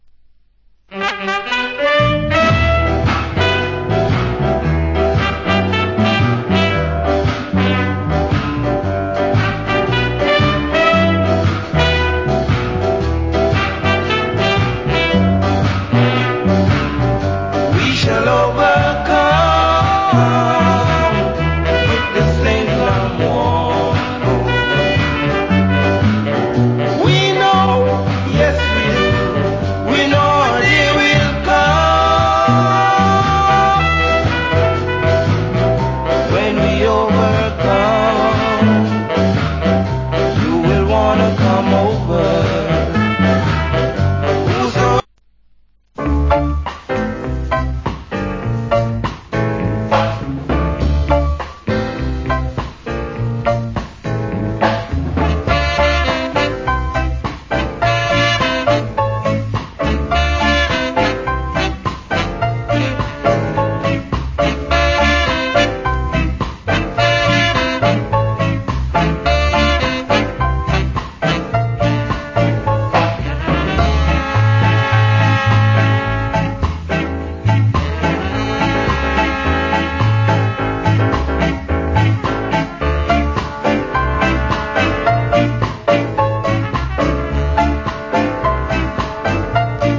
Wicked Duet Ska Vocal.